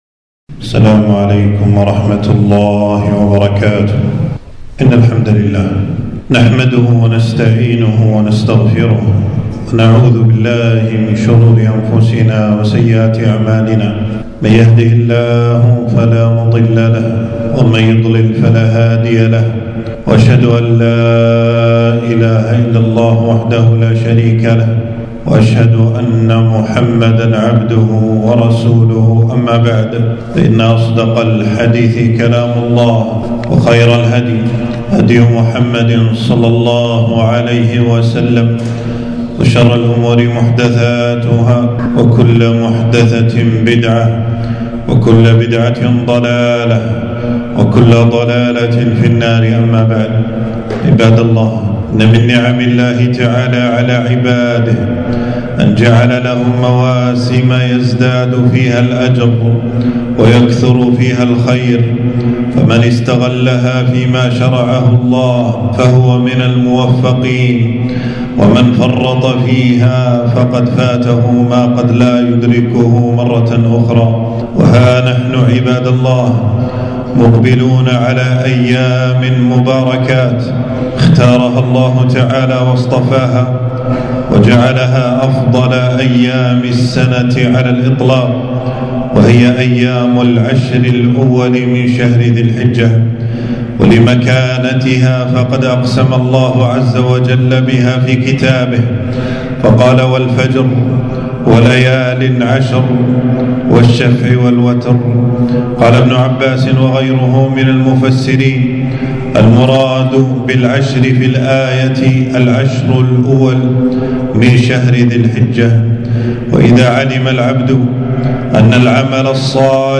خطبة - العشر من ذي الحجة أيام مباركات